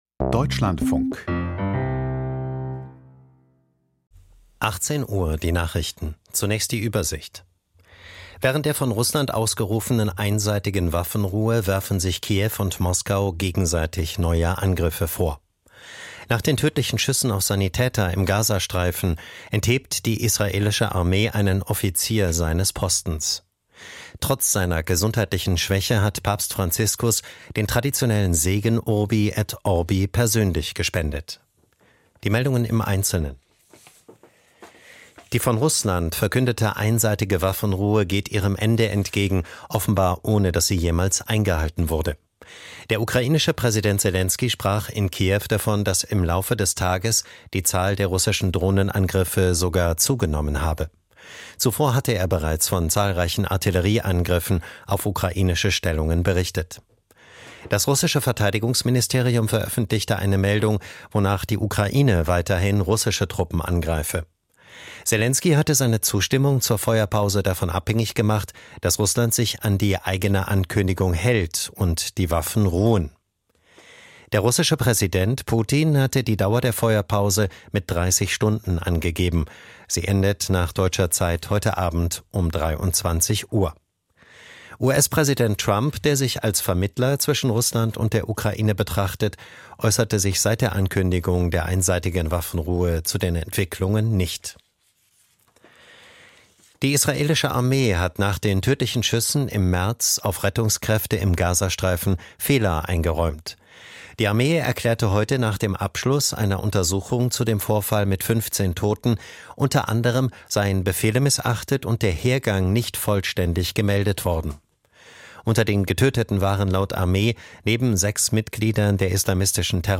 Die Deutschlandfunk-Nachrichten vom 20.04.2025, 18:00 Uhr